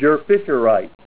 Help on Name Pronunciation: Name Pronunciation: Djerfisherite + Pronunciation
Say DJERFISHERITE Help on Synonym: Synonym: ICSD 200005   PDF 25-635